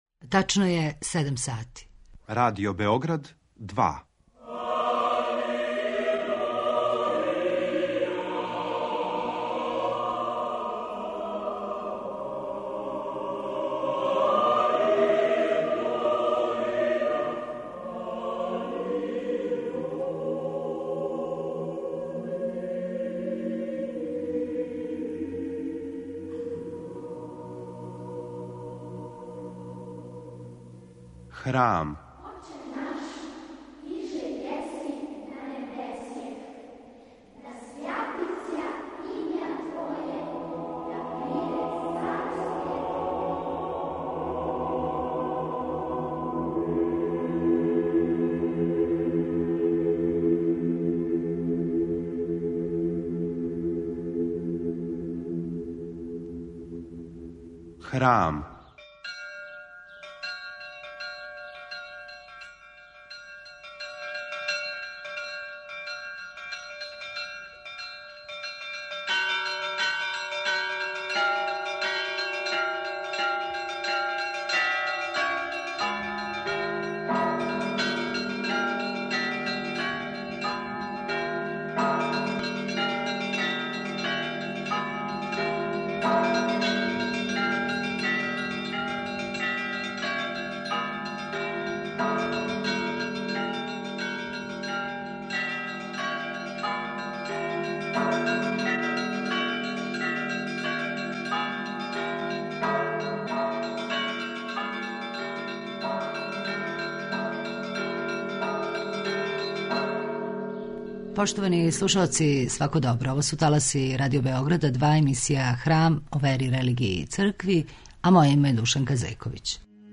Емисија о вери, религији, цркви...